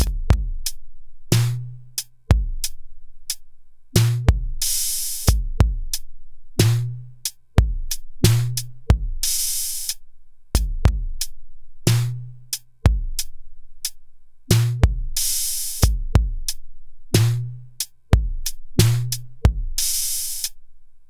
Daily Noize 7-30-10 MFB/DRM 91bpm